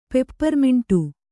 ♪ pepparmiṇṭu